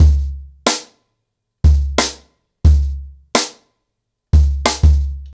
CornerBoy-90BPM.1.wav